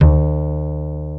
EPM AKUSTI.2.wav